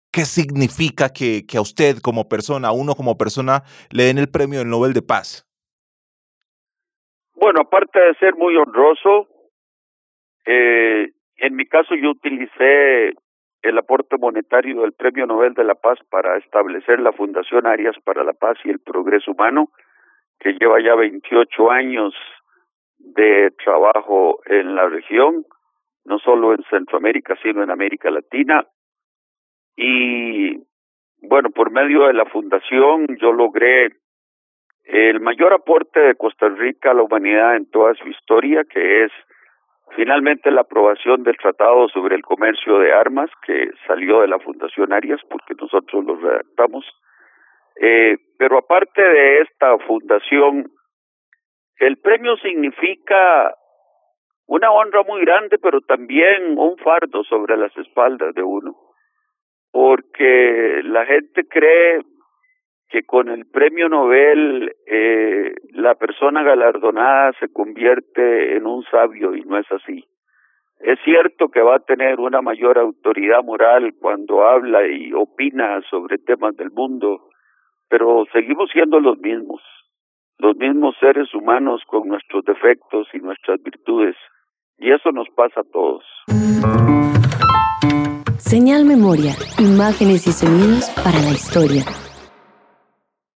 Entrevista al expresidente de Costa Rica y Nobel de Paz de 1987 Óscar Arias Sánchez